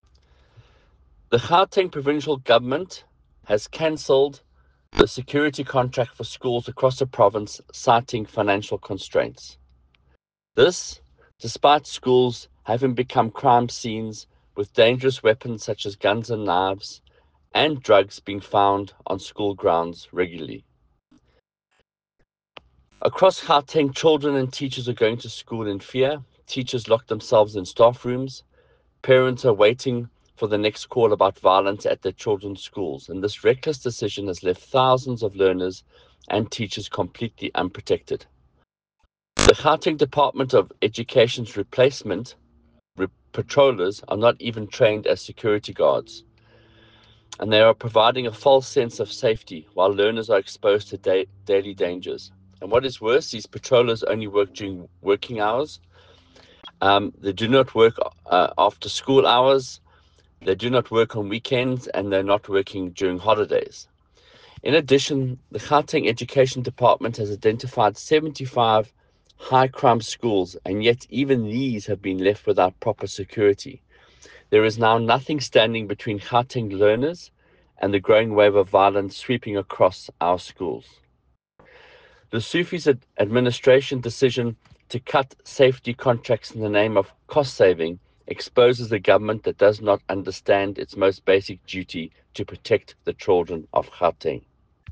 soundbite by Micheal Waters MPL.